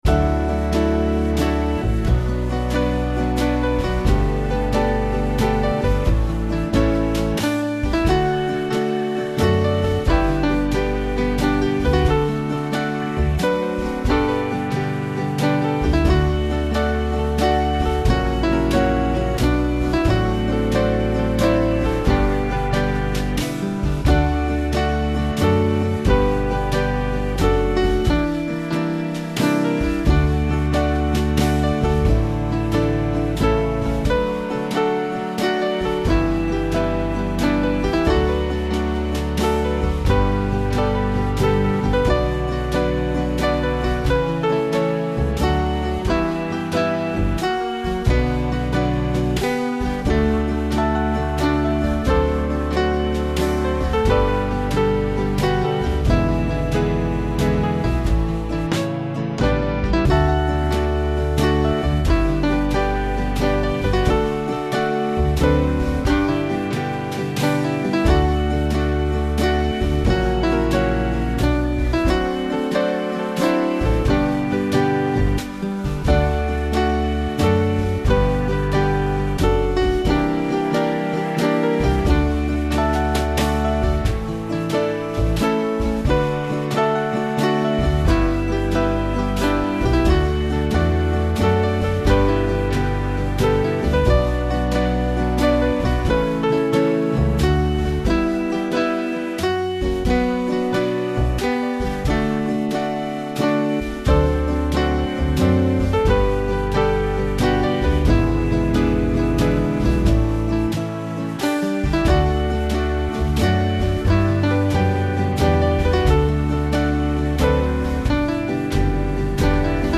gospel tune
My backing has none of that subtlety.